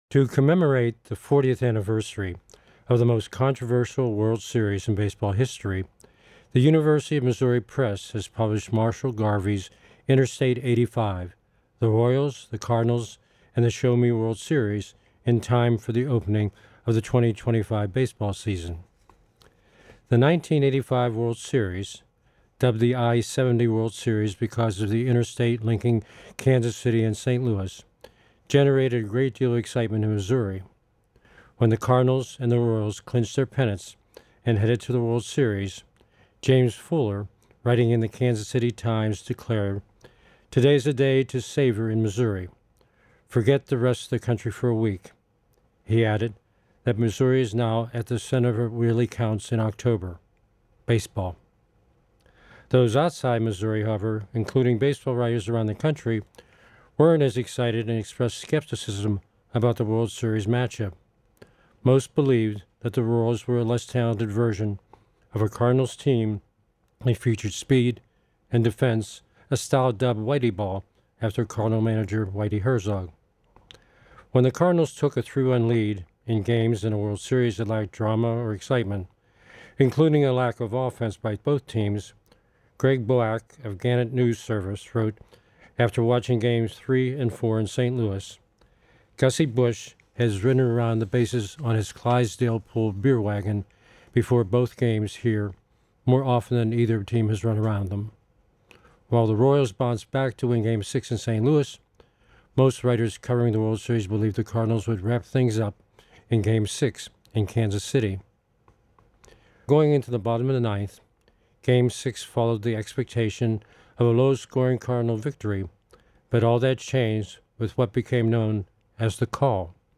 a series of essays and commentaries